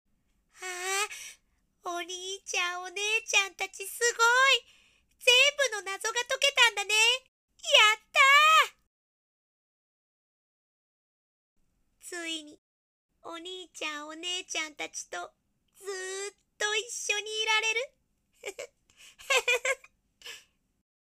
】男児ゴーストセリフ② nanaRepeat